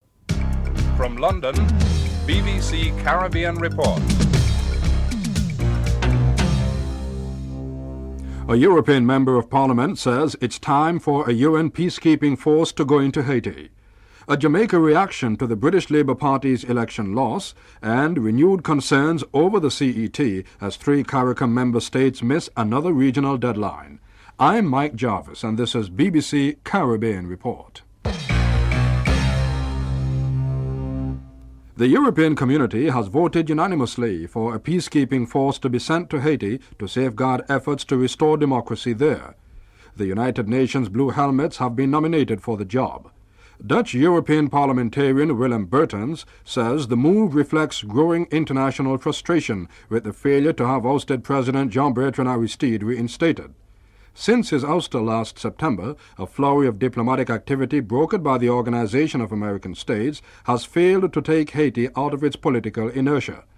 1. Headlines (00:00-00:31)
2. The European Community votes unanimously for the peace keeping force, Blue Helmets, to be sent to Haiti to safeguard efforts to restore democracy. A Dutch Parliamentarian is interviewed (00:32-04:01)